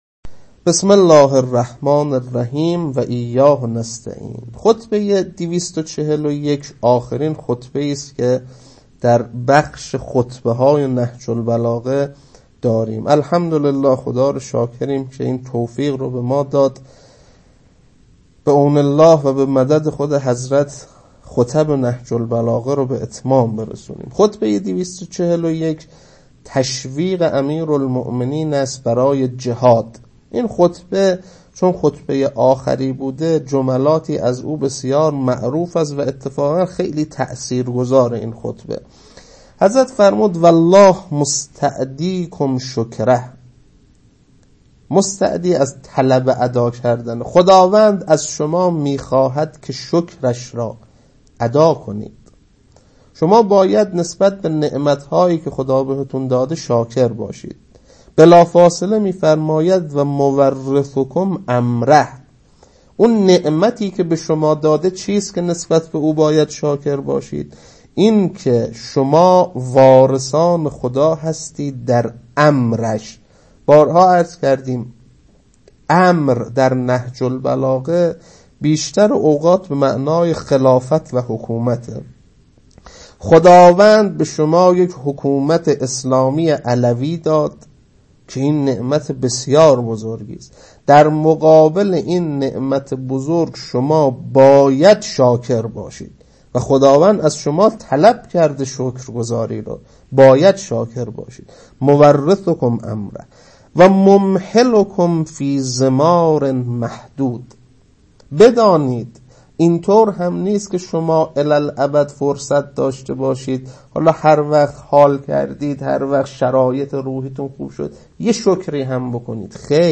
خطبه 241.mp3